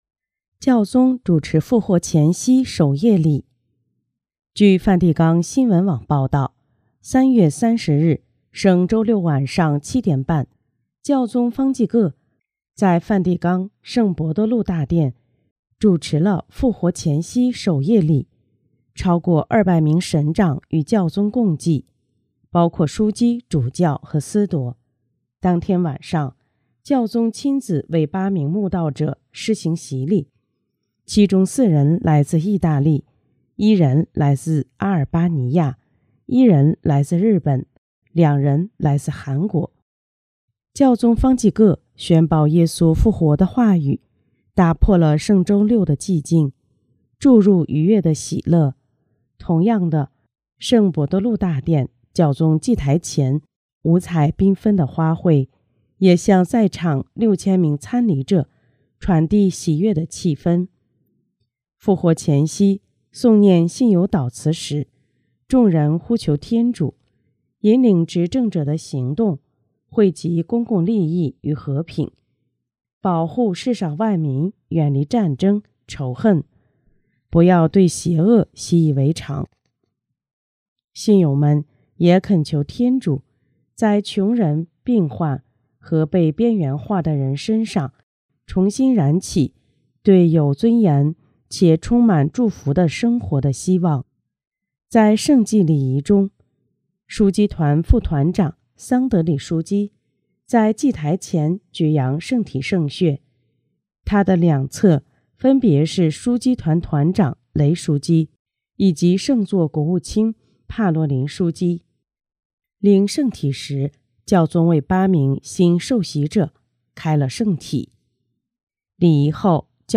【公教新闻】| 教宗主持复活前夕守夜礼（中文旁白全视频）